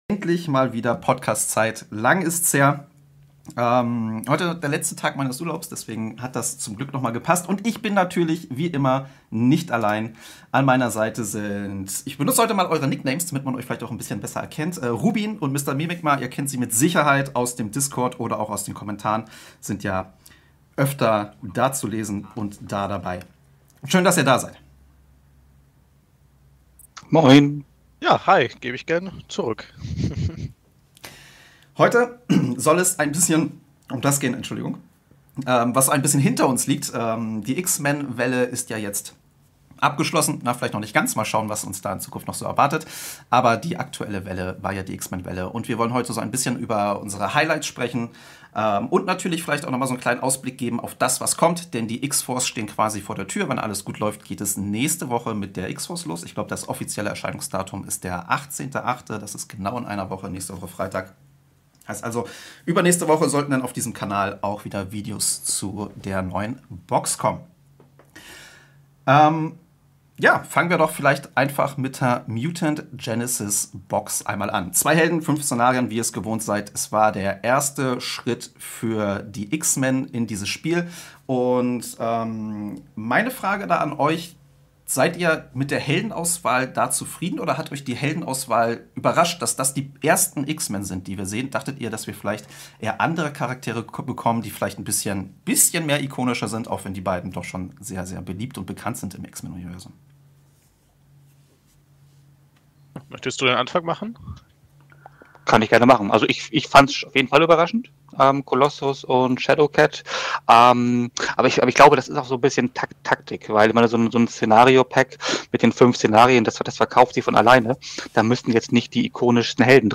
Daher lade ich jedes mal bis zu drei meiner Zuschauer ein um mich über verschiedene Themen zu unterhalten.